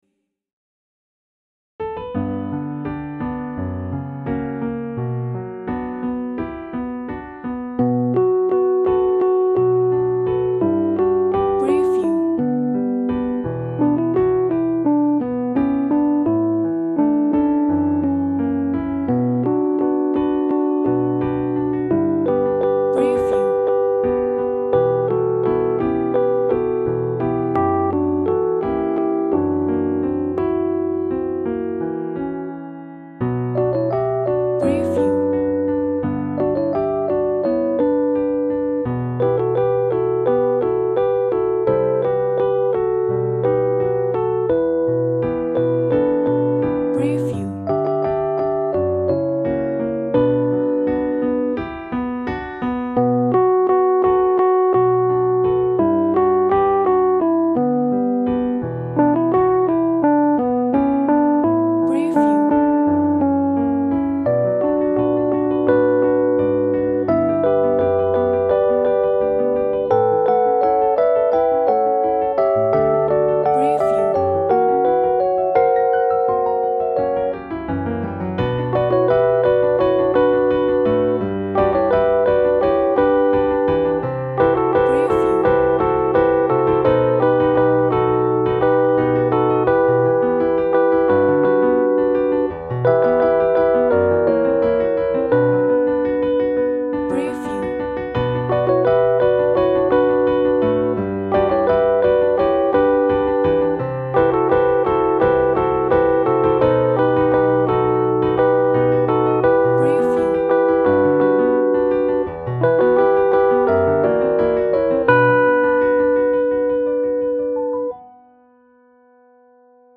Тип: з супроводом Вид хору: SSA / Piano Жанр: пісня